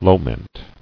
[lo·ment]